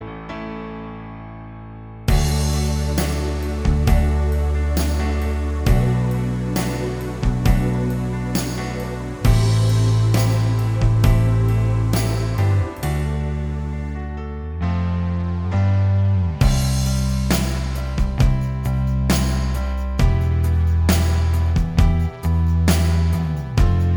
Minus Guitars Pop (1980s) 4:01 Buy £1.50